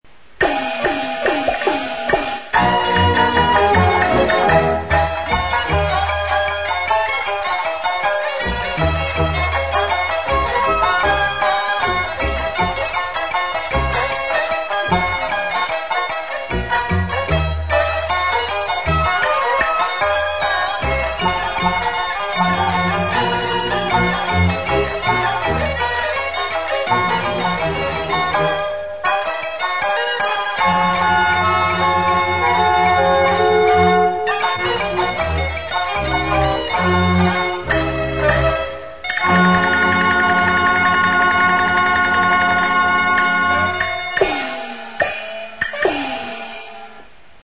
0013-京胡名曲趁黑晚出奇兵.mp3